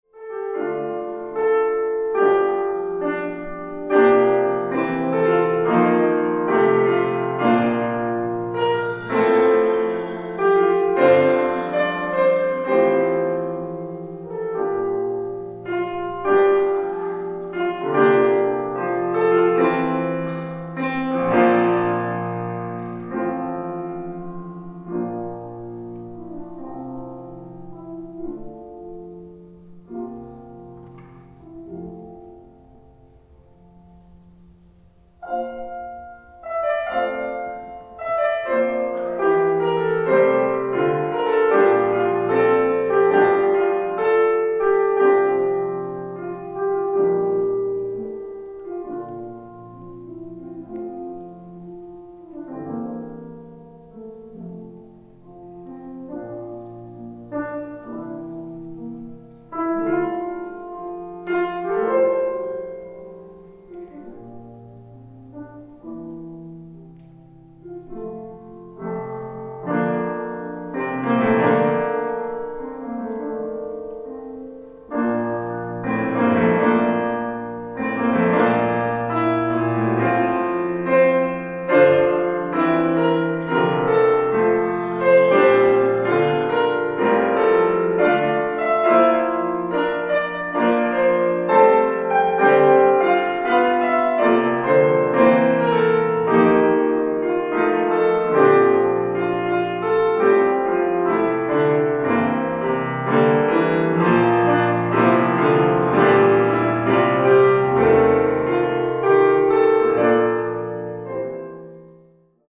ライブ・アット・カーネギー・ホール、ニューヨーク 02/15/2017
※試聴用に実際より音質を落としています。